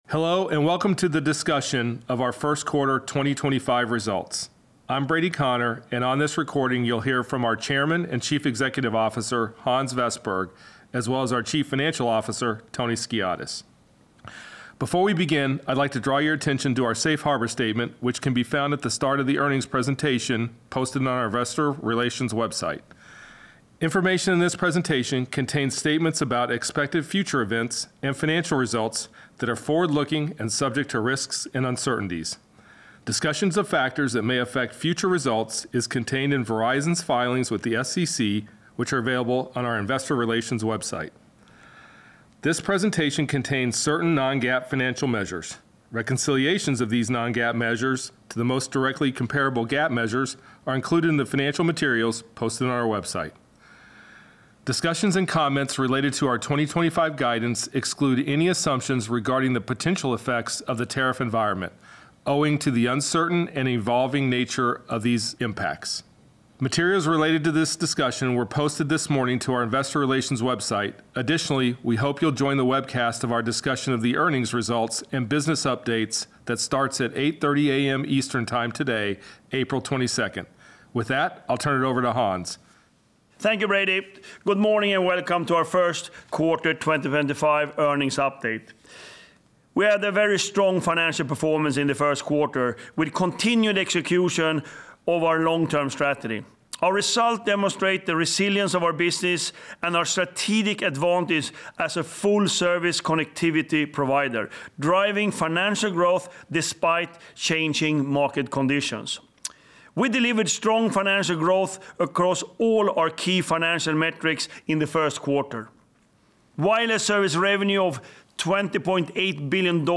1Q 2025 Earnings Conference Call Webcast
1Q25_EarningsCall_PreparedCommentary.mp3